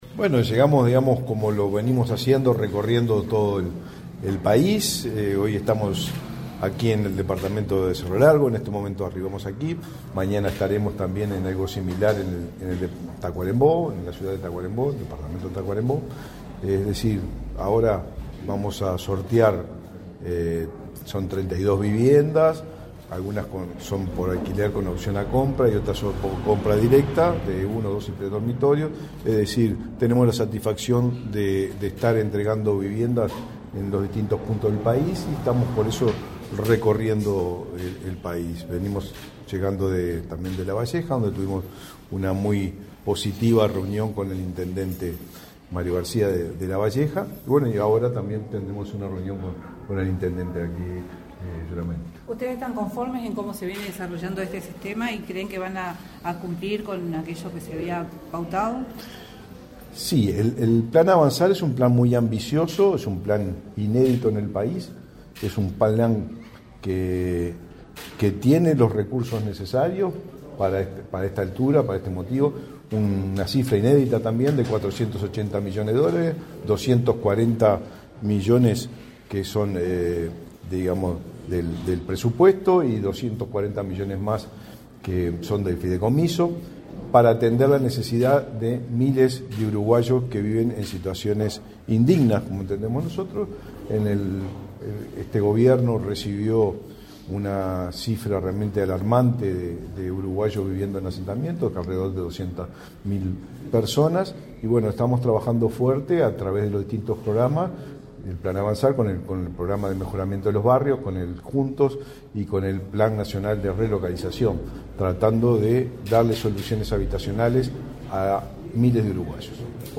Declaraciones a la prensa del titular del MVOT, Raúl Lozano
Declaraciones a la prensa del titular del MVOT, Raúl Lozano 31/08/2023 Compartir Facebook X Copiar enlace WhatsApp LinkedIn Tras participar en el sorteo de 32 viviendas en la ciudad de Melo, en el departamento de Cerro Largo, este 31 de agosto, el titular del Ministerio de Vivienda y Ordenamiento Territorial (MVOT), Raúl Lozano, realizó declaraciones a la prensa.